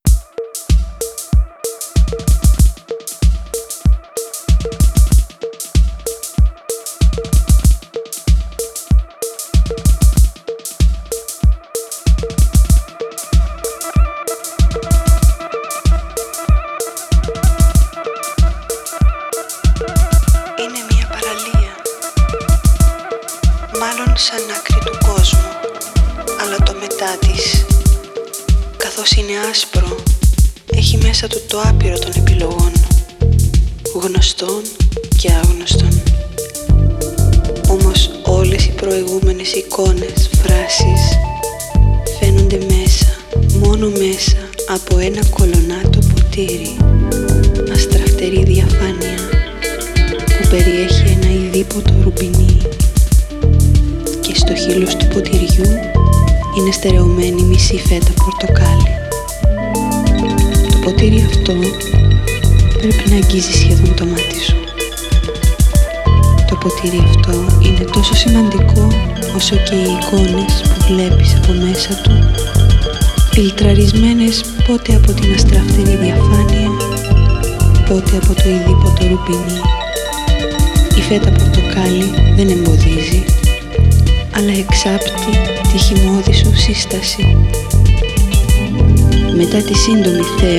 Greek electronic music